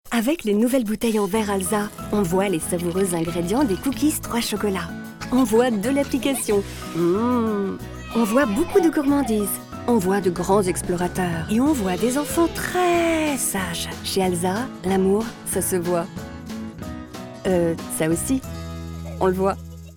INTONATION : PÉTILLANT – SOURIANT